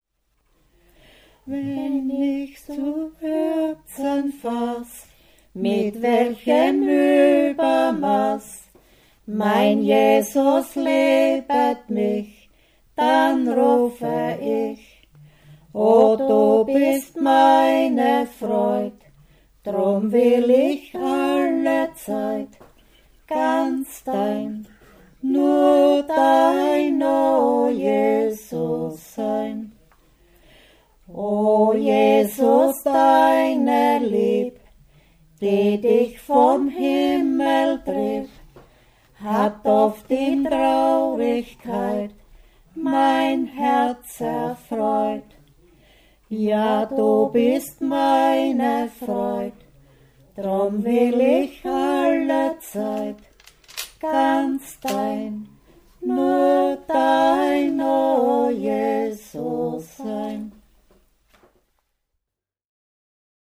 Die „Leichhüatlieder“, welche zwei Nächte lang im Hause eines Verstorbenen vor dem aufgebahrten Toten gesungen wurden. 192 Lieder mit Text- und Melodievarianten, Melodienincipits, 3 CDs mit historischen Tonaufzeichnungen, Wörterbuch des lokalen Dialekts.
Traditional music of the Wechsel, styrian-lower austrian border region, 100 kms south of Vienna. Volume 1 „The religious song“ sung during the farmer’s traditional two-night corpse-watch at the bier in the house of the deceased. 192 songs with text, music and incipits, 3 CDs with historical recordings, dictionary of local dialect.
Church music
Folk & traditional music